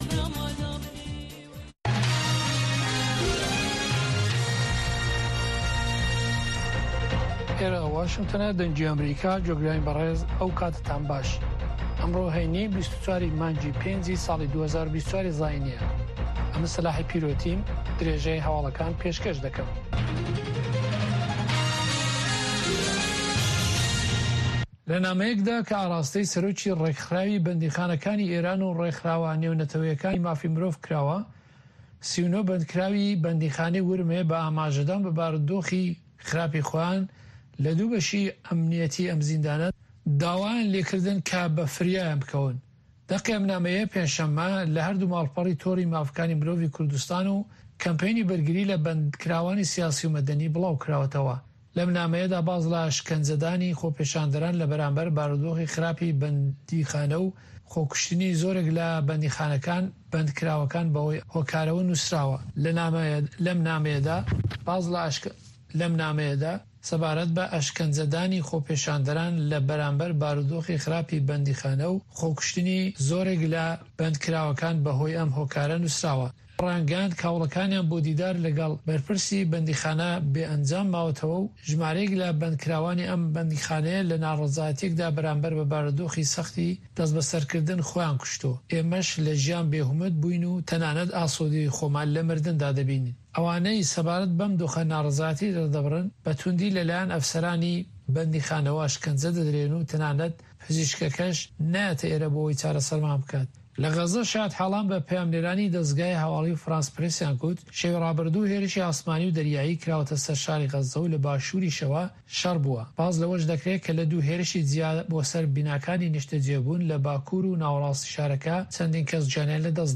Nûçeyên Cîhanê ji Dengê Amerîka